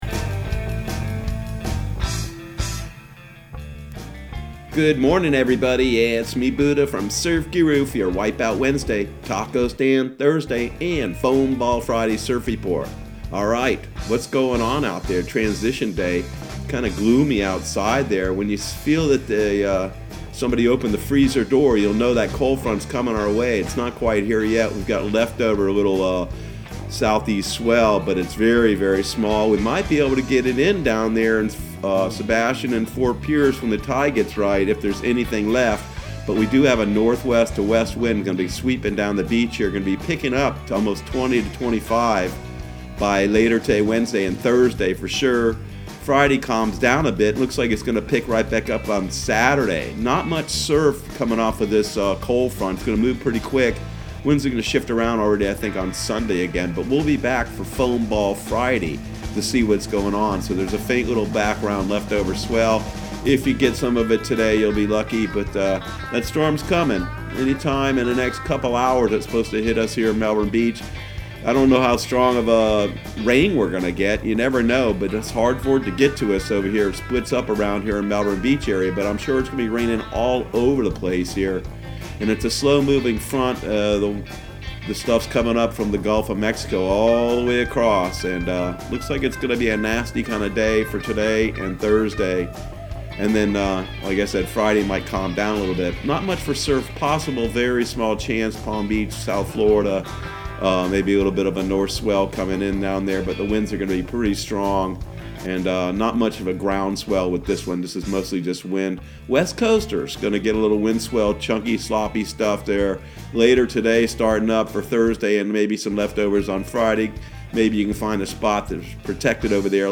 Surf Guru Surf Report and Forecast 02/26/2020 Audio surf report and surf forecast on February 26 for Central Florida and the Southeast.